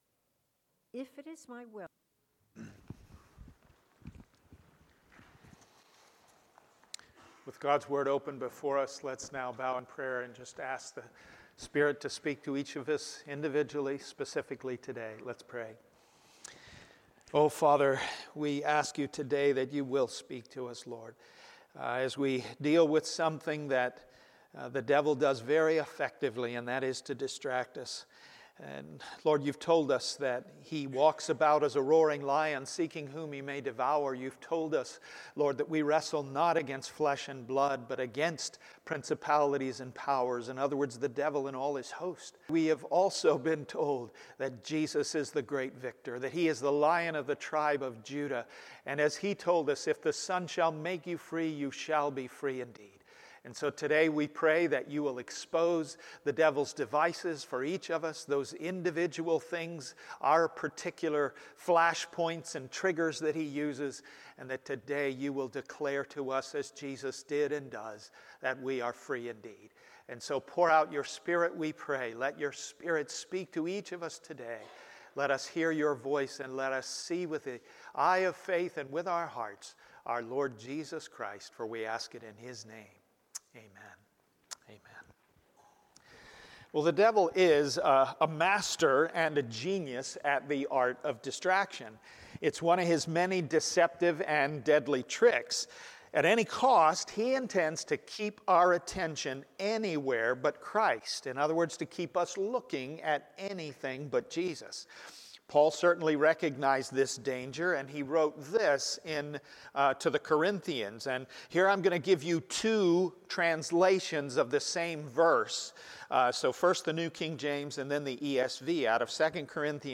Passage: John 21:22 Sermon